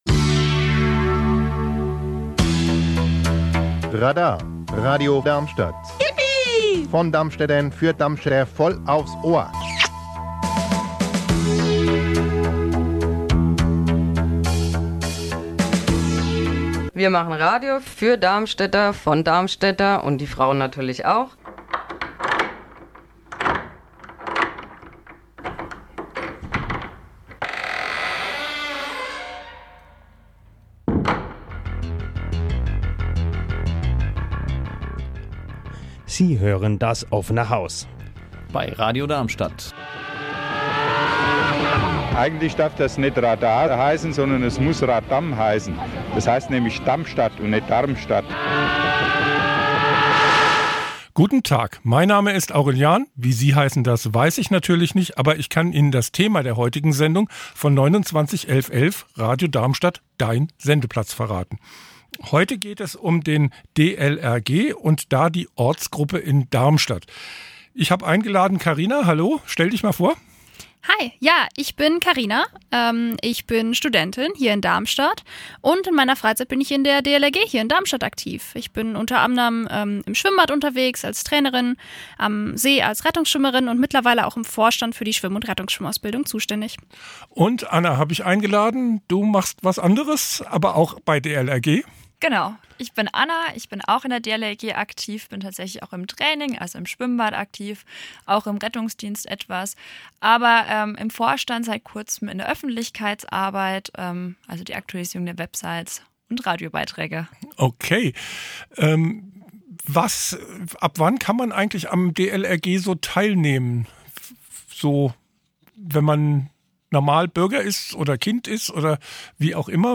Aber auch die Musikwünsche der Mitglieder konnten gespielt werden.